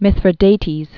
(mĭthrĭ-dātēz) Known as "Mithridates the Great." 132?-63 BC.